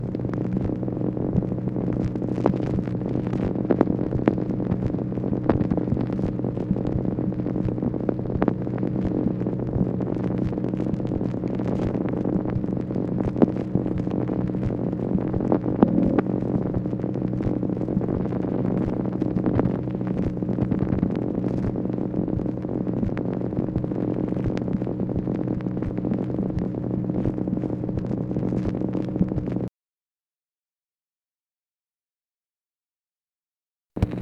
MACHINE NOISE, July 15, 1965
Secret White House Tapes | Lyndon B. Johnson Presidency